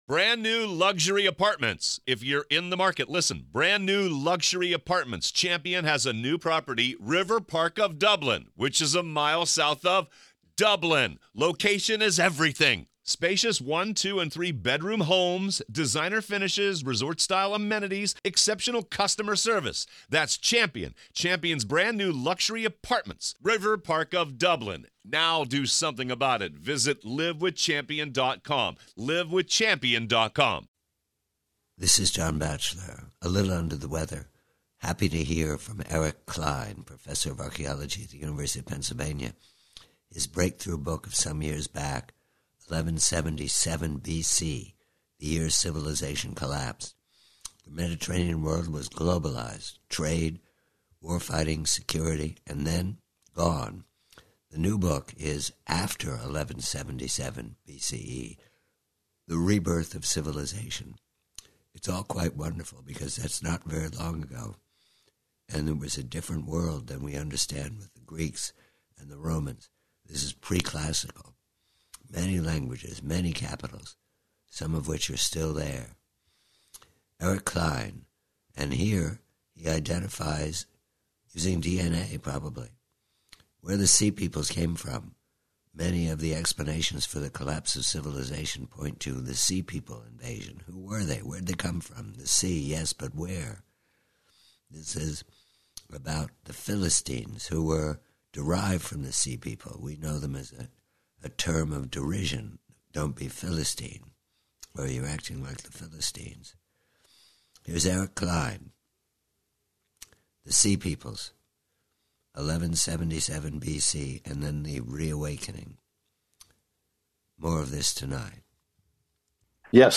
PREVIEW: PHILISTINES: SEA PEOPLES: Conversation with archaeologist Eric Cline for his new book, "After 1177 BC," re the awakening of the collapsed Bronze Age into the powers of the Iron Age in Mesopotamia, the Levant, and Egypt.